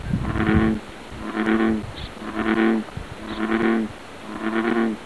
Click here to hear a recording of a vocal frog by the pond.
This was actually recorded at the frog pond, so what you hear on the page, you will most likely hear on the hike!)
FrogPond.wav